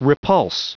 Prononciation du mot repulse en anglais (fichier audio)
Prononciation du mot : repulse